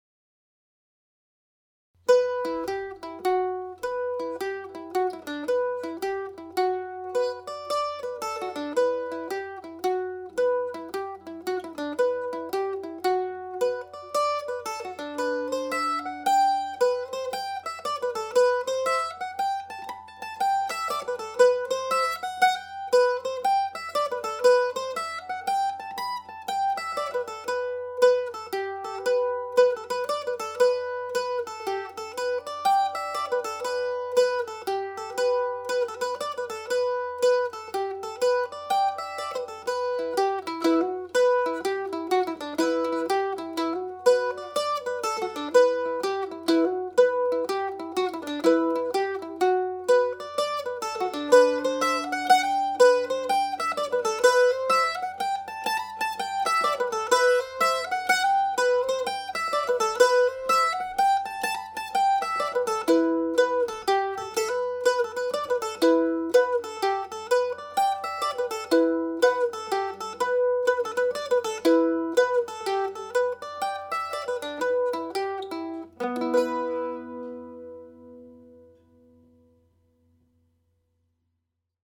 Slip jig (E Minor)
played at slip jig speed
The tune is played in the key of E minor which gives it a powerful mystical sound.